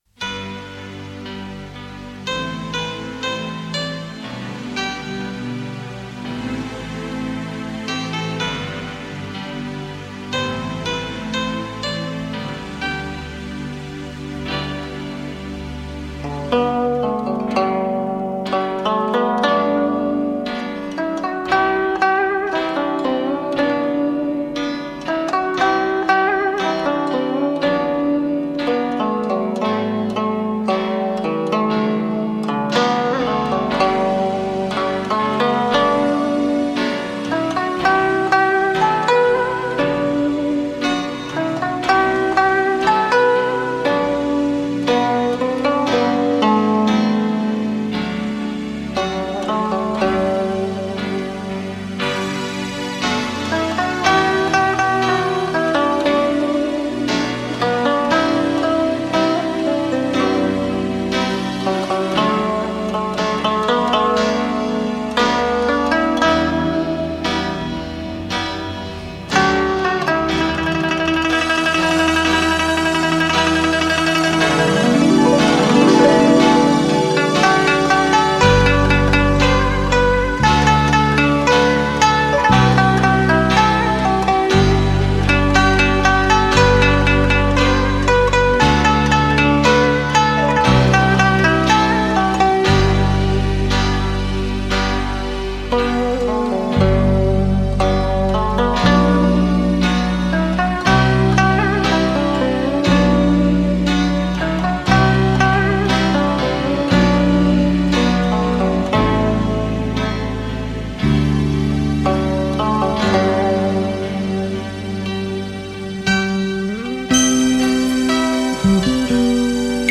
再配合电子乐的迷离色彩